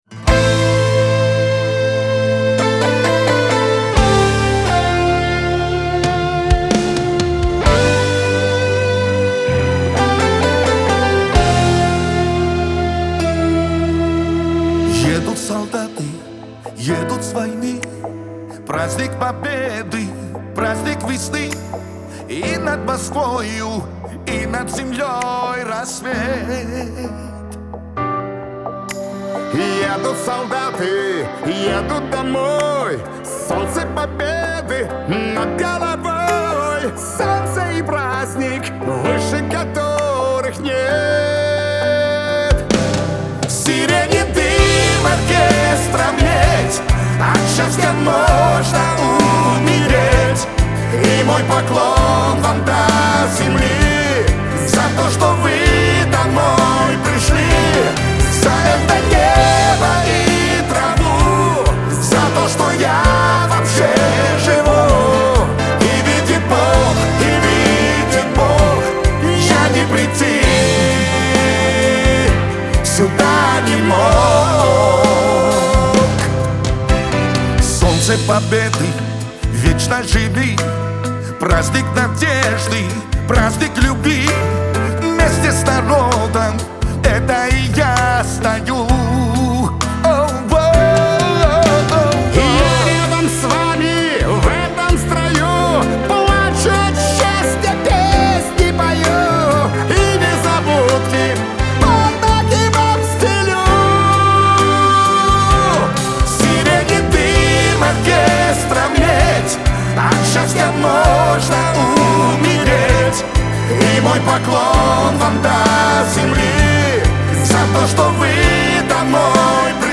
Трек размещён в разделе Русские песни / Эстрада / 2022.